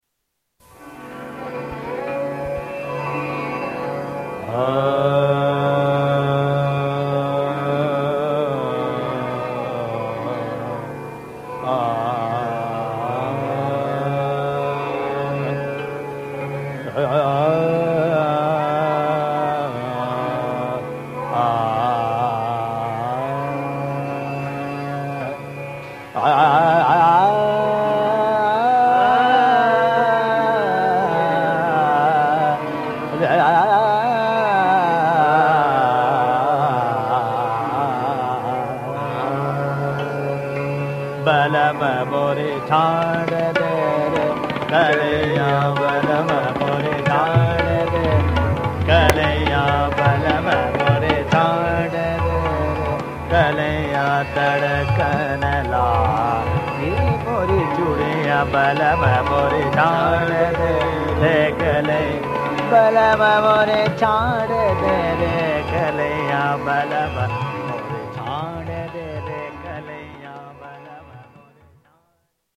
Indian Music